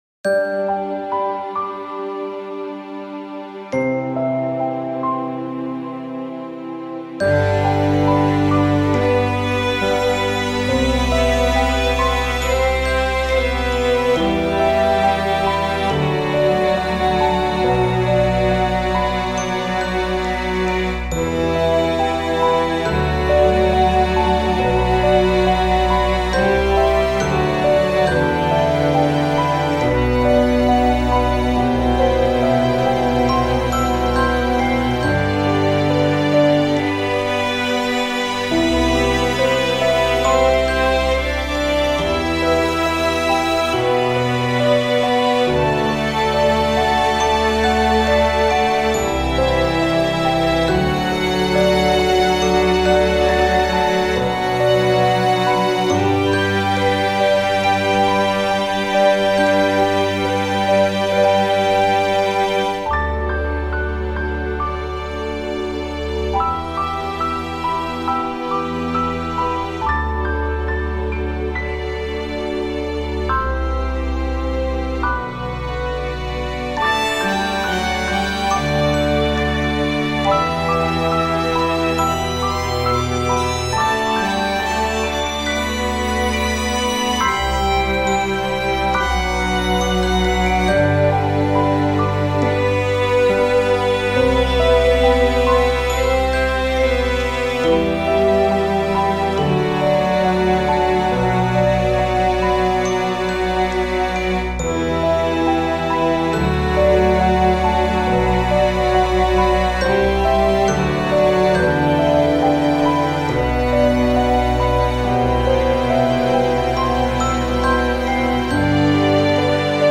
センチメンタルになるような静かでゆっくりしたBGMです。
BGM バイオリン ストリングス バラード エンディング 別れ 切ない 静か 優しい 感動 穏やか